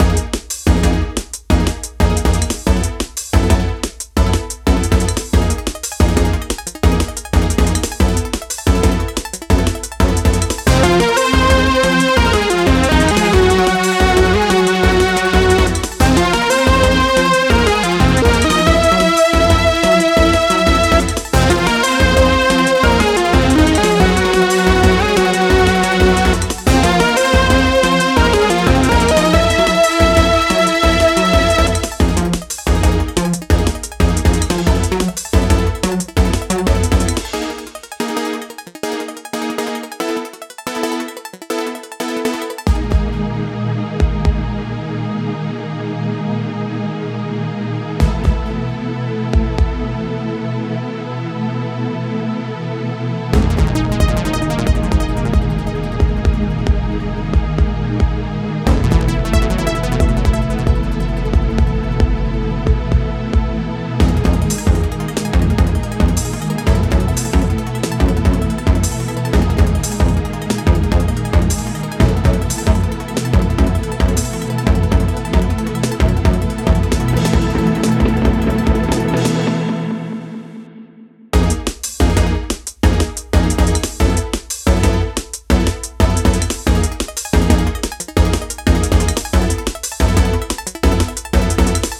タグ: サイバー
クールでサイバー、ちょっと焦り